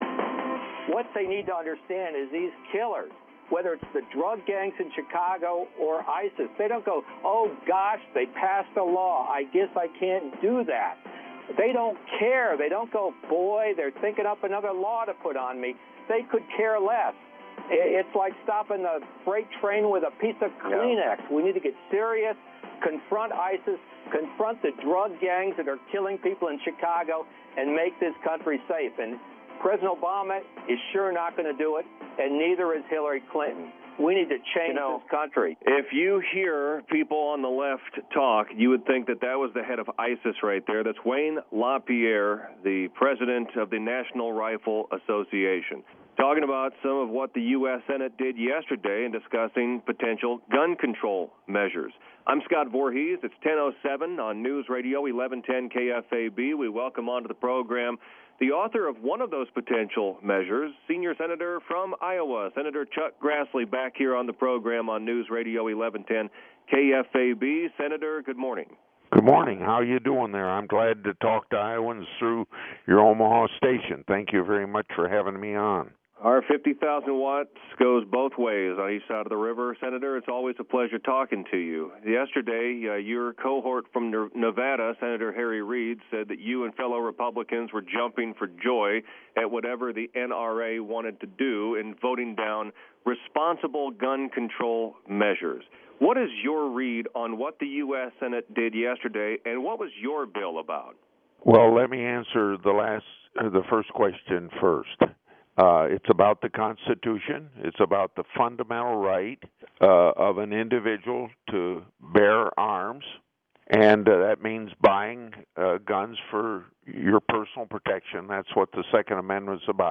Grassley Live on KFAB